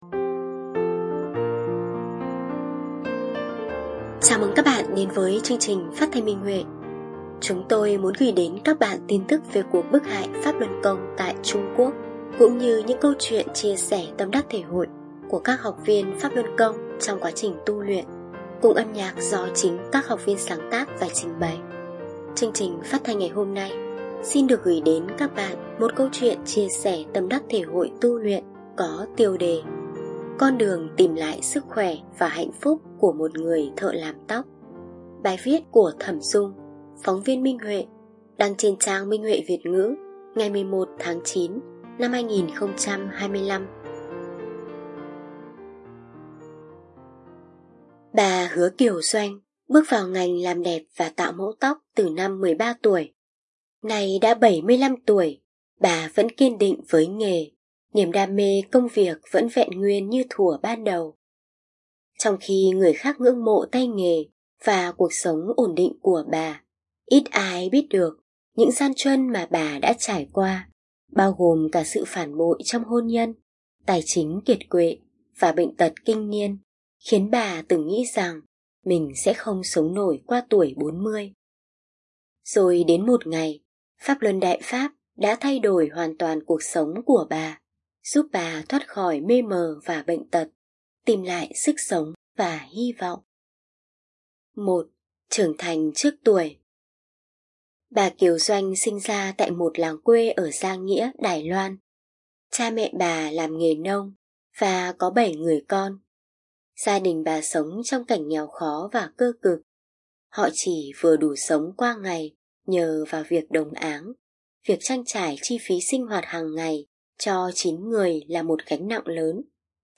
Chúng tôi muốn gửi đến các bạn thông tin về cuộc bức hại Pháp Luân Công tại Trung Quốc cũng như những câu chuyện chia sẻ tâm đắc thể hội của các học viên trong quá trình tu luyện, cùng âm nhạc do chính các học viên sáng tác và trình bày.
Chương trình phát thanh số 1545: Bài viết chia sẻ tâm đắc thể hội trên Minh Huệ Net có tiêu đề Con đường tìm lại sức khỏe và hạnh phúc của một người thợ làm tóc, bài viết của phóng viên Minh Huệ.